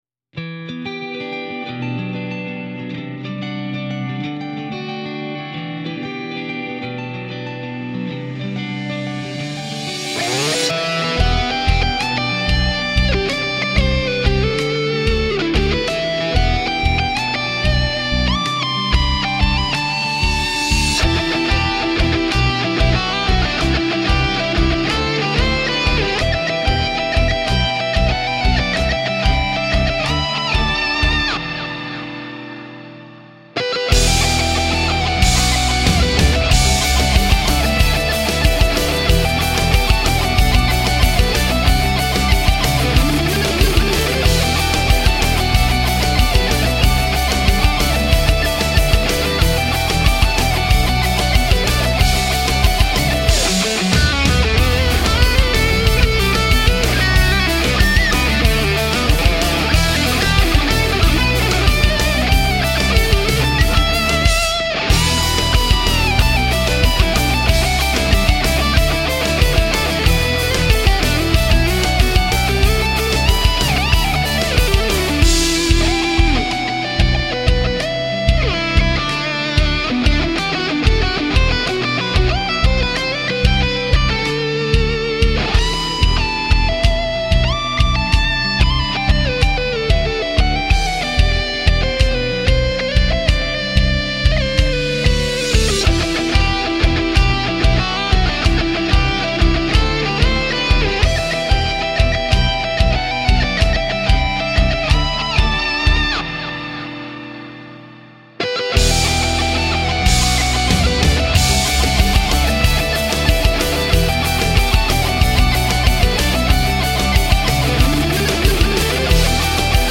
Rock, Metal